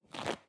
Toilet Paper Tear Short Sound
household